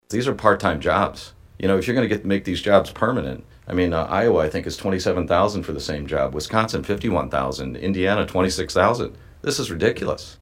State Representative Martin McLaughlin, who voted against the raises, says it is embarrassing.